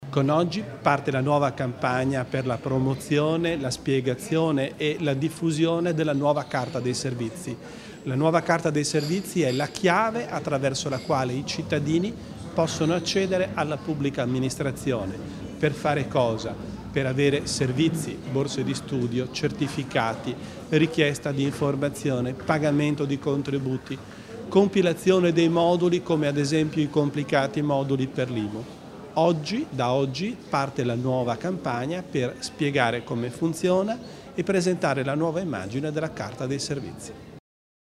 L'Assessore Bizzo spiega gli ultimi sviluppi della Carta dei Servizi